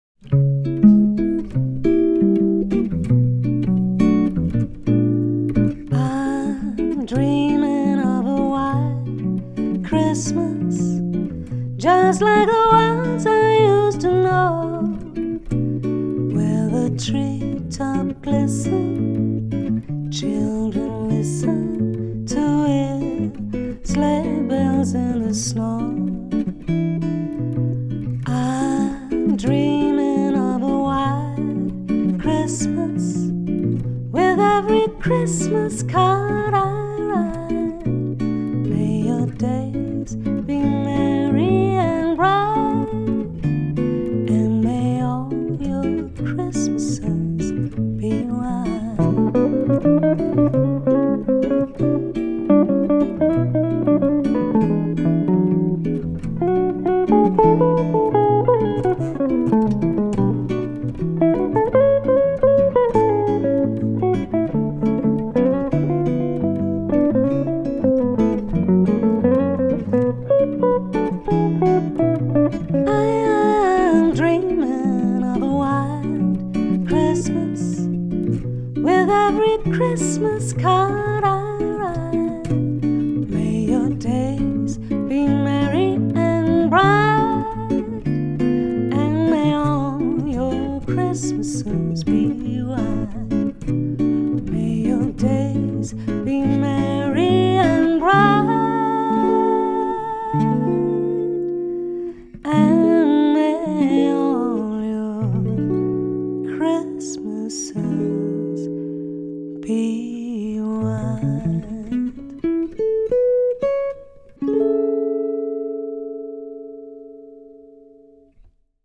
Jazz Duo Gitarre und Gesang, Live Jazz